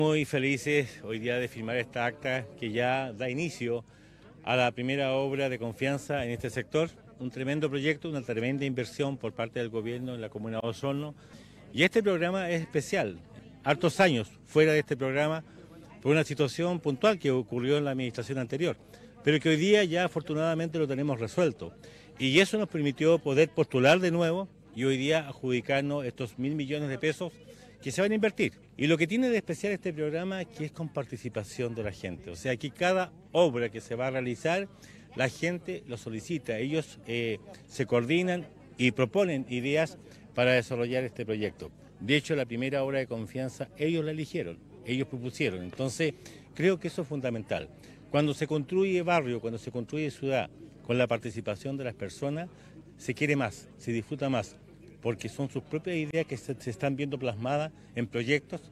El sábado recién pasado, se realizó el hito inaugural del Programa de Recuperación de Barrios en la Población García Hurtado de Mendoza, donde se ejecuta el Programa Quiero Mi Barrio del Ministerio de Vivienda y Urbanismo, en coordinación con la Municipalidad de Osorno.
En tanto el Alcalde Emeterio Carrillo, señaló que de este modo se inicia la obra de confianza, escuchando a los vecinos para desarrollar este proyecto que beneficiará a todo el sector dando prioridad a la participación comunitaria.